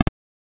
8 Bit Click (1).wav